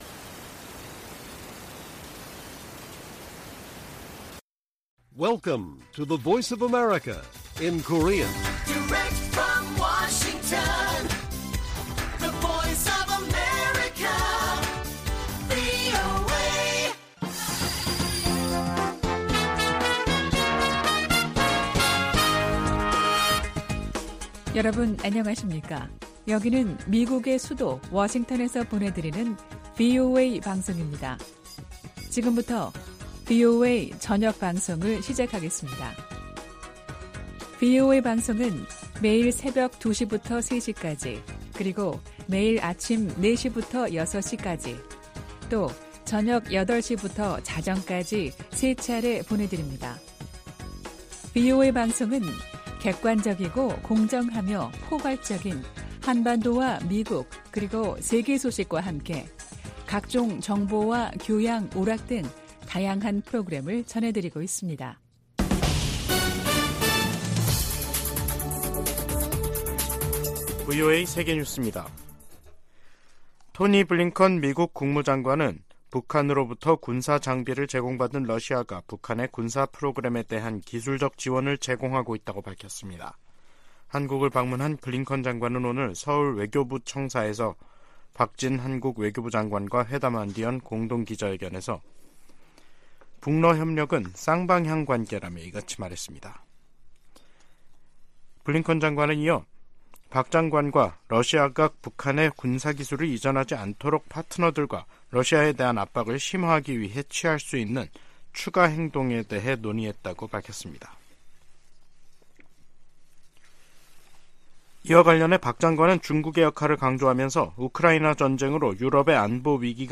VOA 한국어 간판 뉴스 프로그램 '뉴스 투데이', 2023년 11월 9일 1부 방송입니다. 토니 블링컨 미 국무장관과 박진 한국 외교장관이 9일 서울에서 만나 북러 군사협력 문제 등 현안을 논의했습니다. 백악관은 미국이 한반도 비상상황 대비를 위해 늘 노력하고 있다며, 한국의 중동 개입은 '주권적 결정' 사안이라고 강조했습니다. 주요7개국(G7) 외교장관들이 북한의 지속적인 대량살상무기 개발과 러시아로의 무기 이전을 강력히 규탄했습니다.